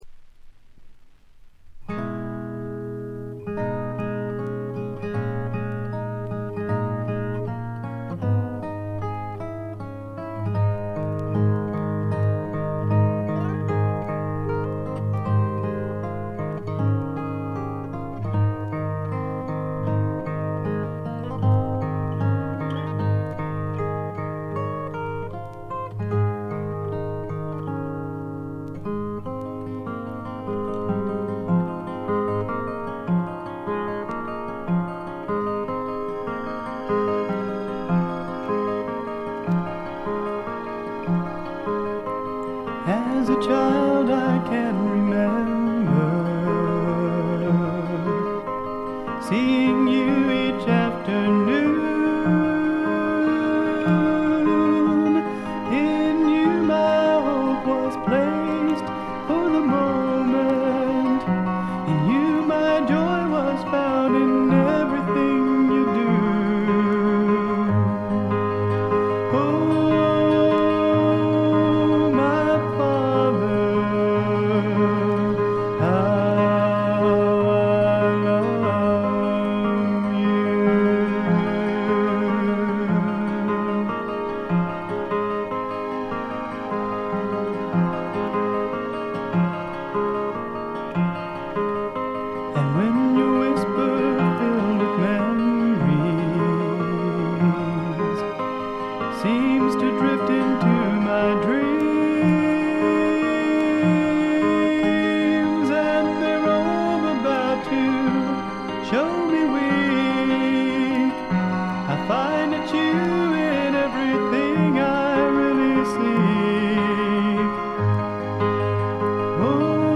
軽いバックグラウンドノイズ。ところどころでチリプチ。
ローナーフォーク、ドリーミーフォークの逸品です。
触れた瞬間に消えてなくなりそうな繊細でかそけき世界が、とてもいとおしく魅力的です。
試聴曲は現品からの取り込み音源です。